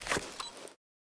pda_draw.ogg